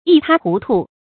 一塌胡涂 注音： ㄧ ㄊㄚ ㄏㄨˊ ㄊㄨˊ 讀音讀法： 意思解釋： 見「一塌糊涂」。